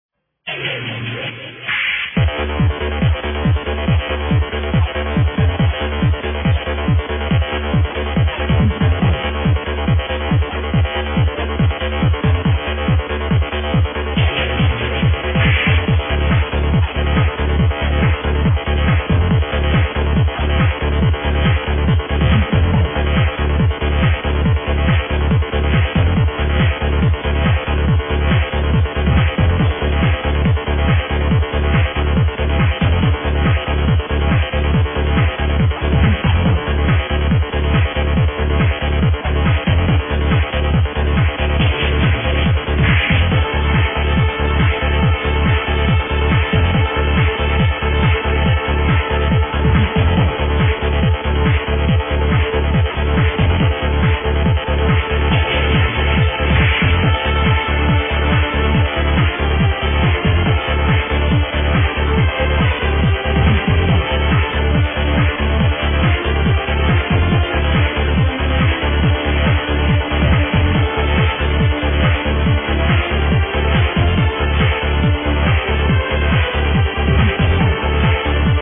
IMHO, the track I want ID'ed is more in a 1996 goa style than anything else...